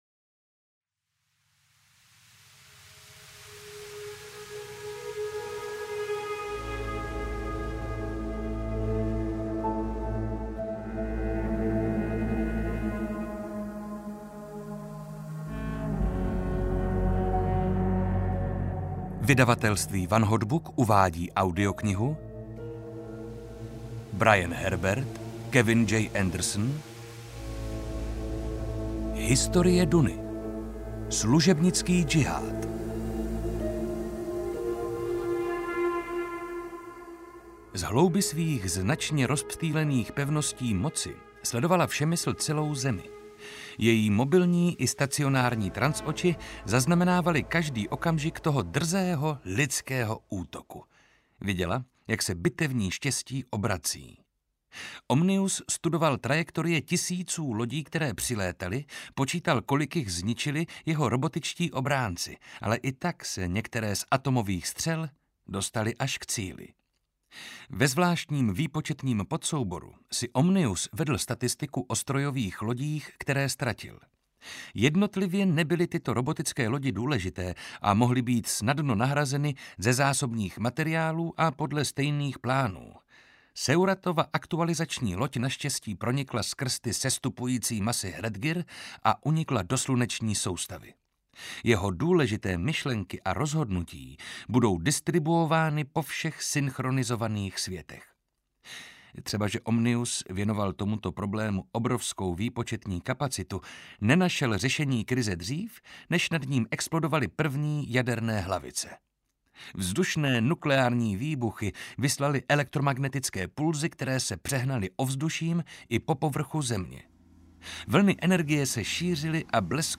Historie Duny – Služebnický džihád audiokniha
Ukázka z knihy
• InterpretJana Stryková, Marek Holý